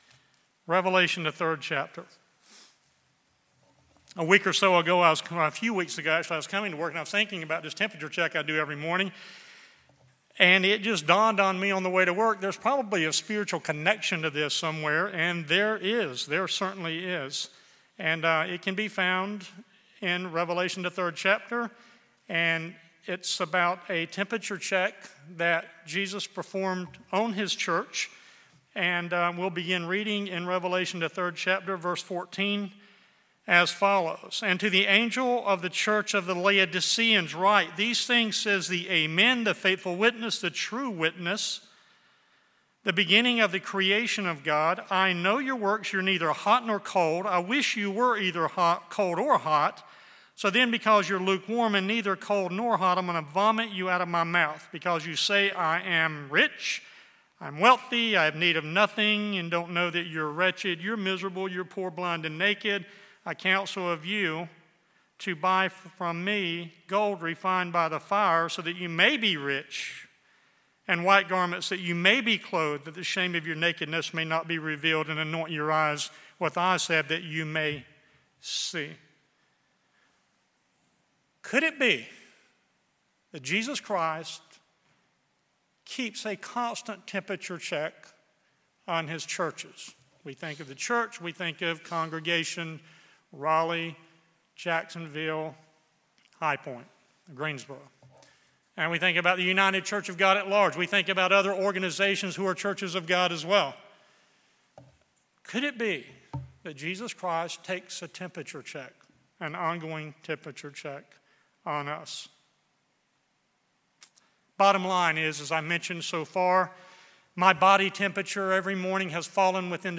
An exhortation to take your own spiritual temperature check to see if you are hot, cold, or likewarm.